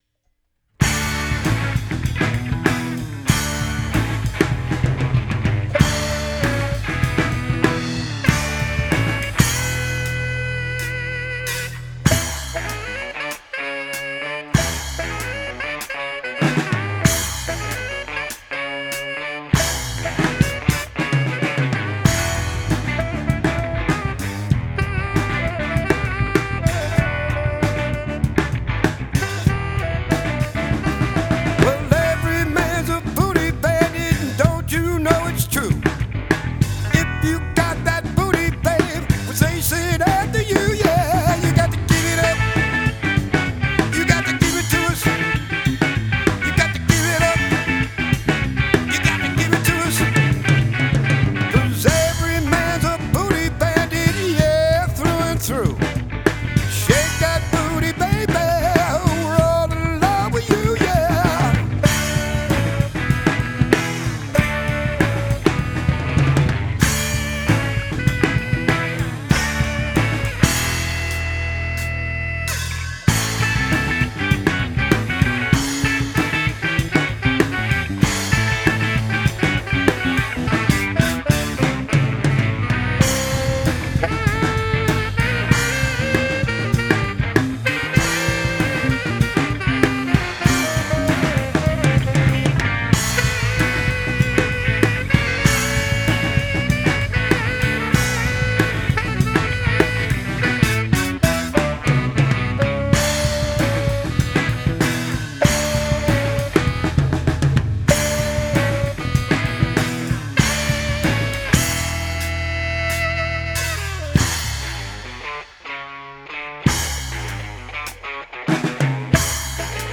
I was able to get the kick "decent" I think. I EQd it and put some compression on it and brought the incoming signal into the board up.
I brightened up the drums and took the two snare tracks and panned them a bit. I also took off about half the FX on the drums which was probably causing muddiness? Brought the sax down behind the vocals and up where needed.